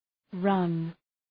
Προφορά
{rʌn}